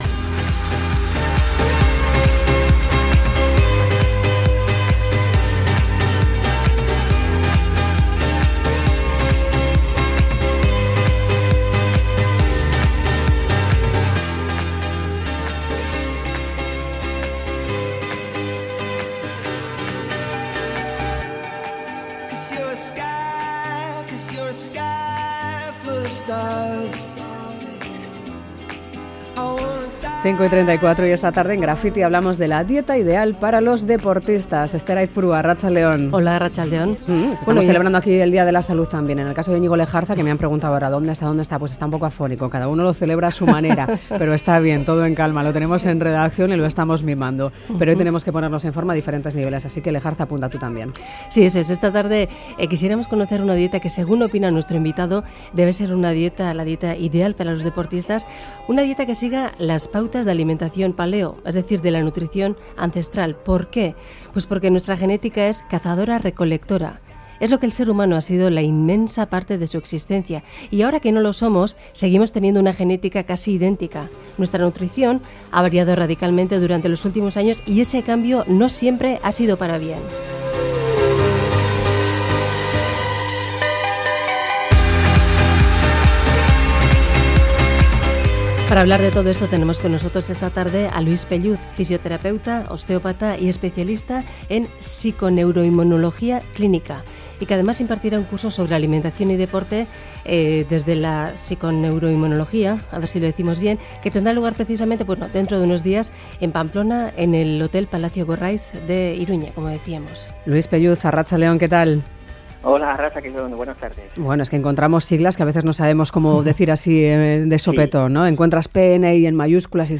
Radio Euskadi GRAFFITI ¿Cuáles son los beneficios de la dieta evolutiva?